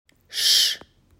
• sch (→ 3 Buchstaben, aber 1 Laut)
sch-laut.m4a